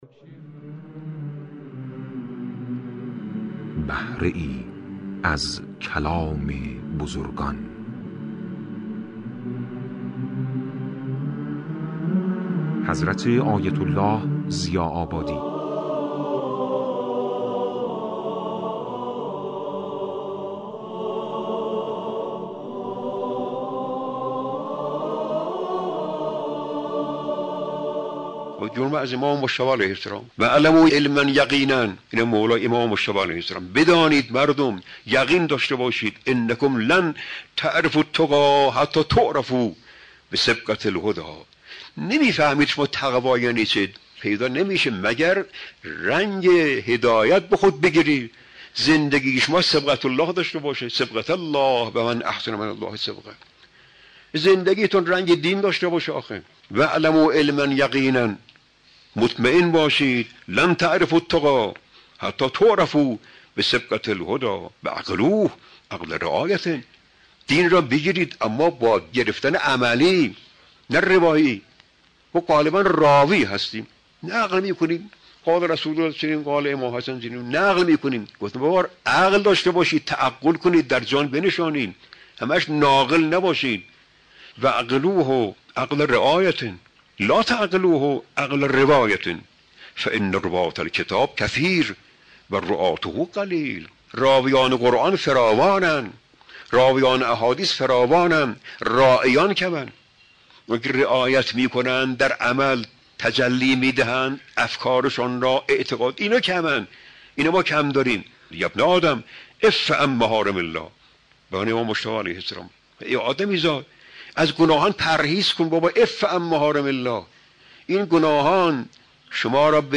منبع: راویان و راعیان سخنران: آیت الله سید محمد ضیاء آبادی